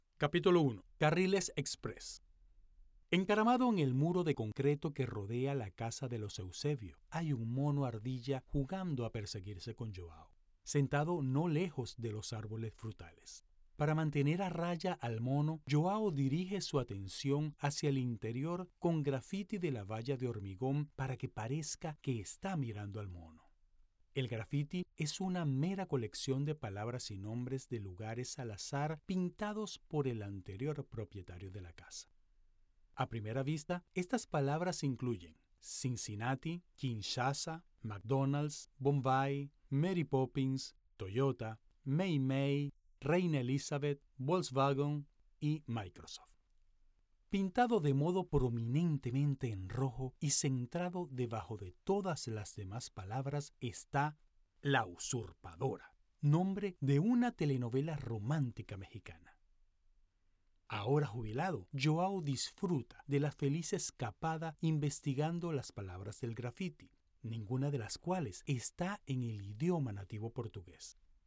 A friendly tenor voice performer in LATAM neutral, Mexican accent and English with Hispanic accent. Listen to an authentic, confident, and energetic voice ready for your projects....
0401Spanish_Voice_Actor_Needed_for_YA_Novel.mp3